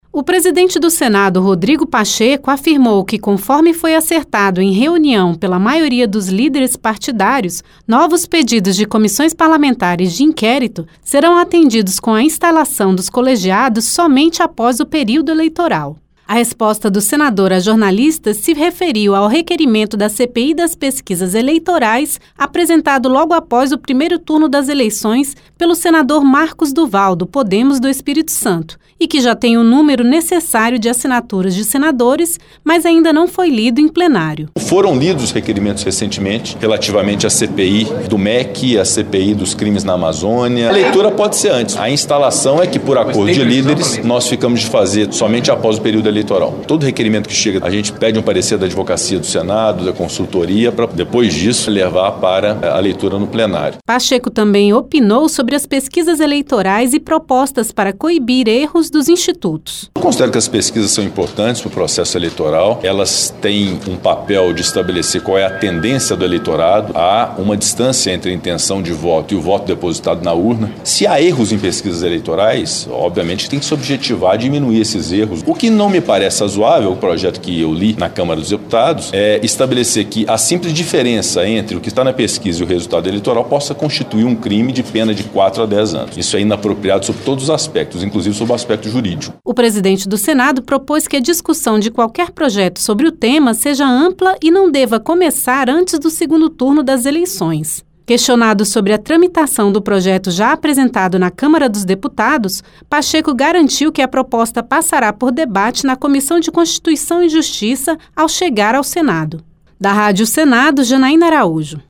O presidente do Senado, Rodrigo Pacheco, falou sobre o acordo com líderes partidários para que a instalação de comissões parlamentares de inquérito aconteçam somente após o período eleitoral. O senador também pediu cautela na análise de propostas para coibir erros de institutos de pesquisas eleitorais.